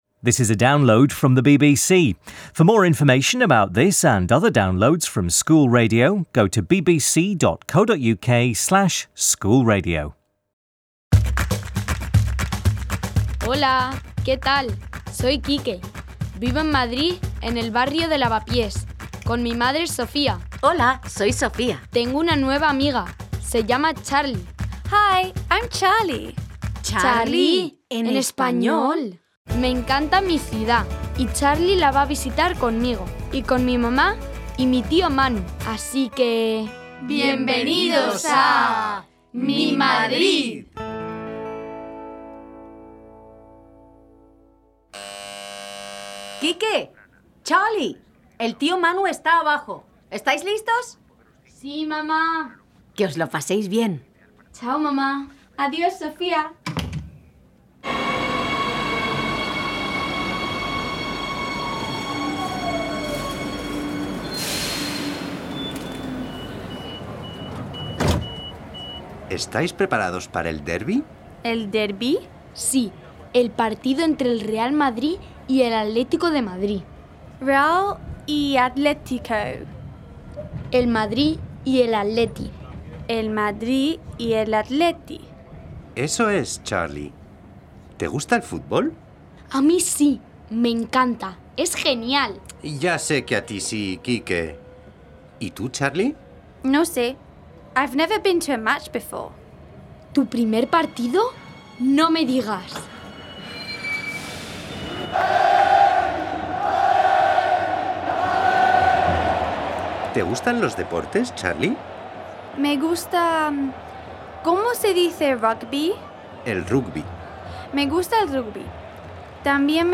Uncle Manu takes Charlie and Quique to the Madrid derby: Real Madrid vs Atlético Madrid. Manu tells a fantastical story about the rivalry between the two clubs' stadiums and also sings about his love of football. Key vocabulary focuses on different sports and the key grammar includes comparative adjectives and superlatives.